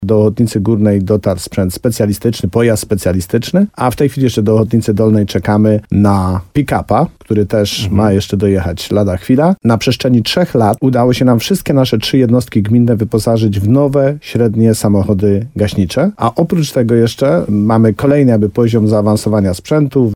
Jak mówi wójt Tadeusz Królczyk, to nie koniec doposażania miejscowych remiz.